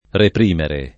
repr&mere] v.; reprimo [repr&mo] — pass. rem. repressi [repr$SSi]; part. pass. represso [